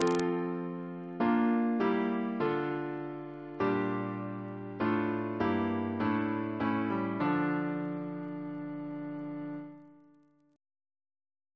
Single chant in D minor Composer: Donald B. Eperson (1904-2001) Reference psalters: ACB: 347